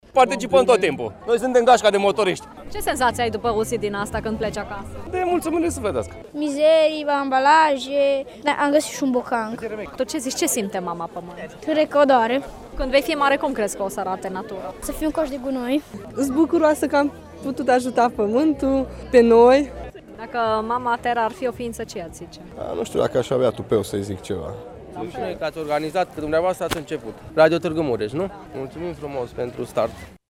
a strâns câteva trăiri ale participanților